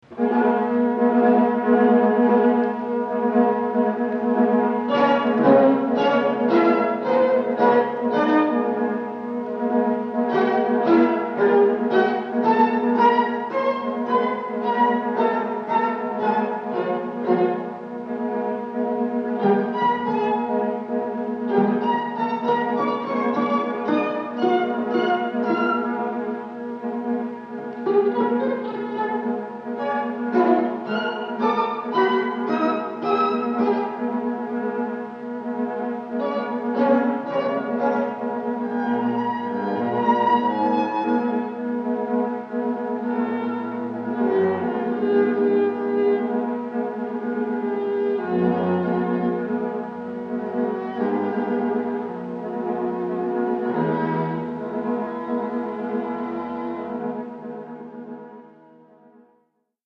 String Trio